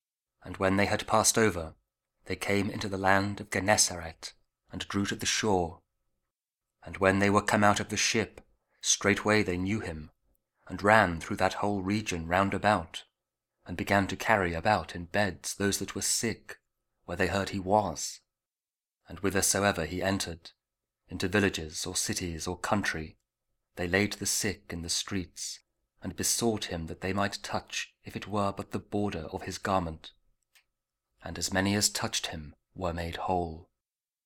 Mark 6: 53-56 – Week 5 Ordinary Time, Monday (Audio Bible KJV, Spoken Word)